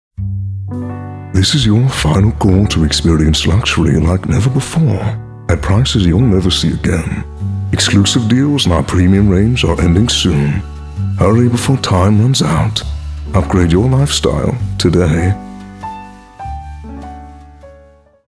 authoritative, character, confident, Deep, Quirky, smooth
Soft Sell Luxury